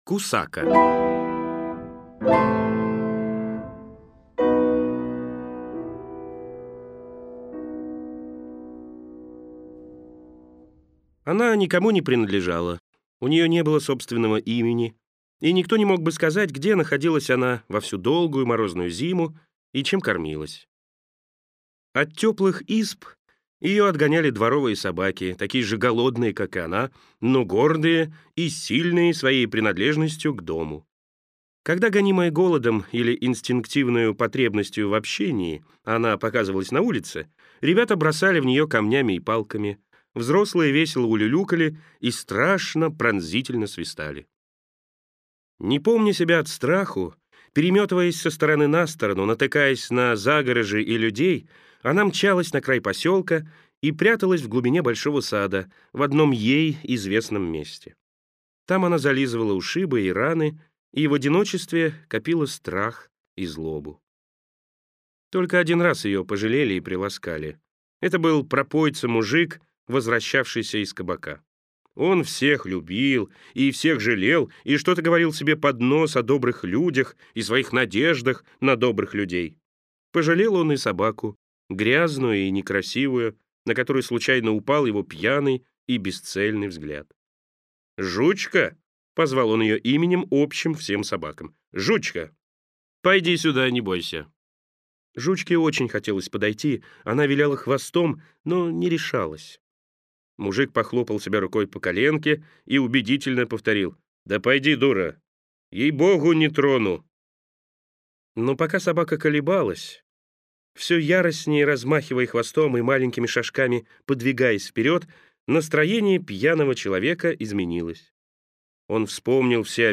Aудиокнига Рассказы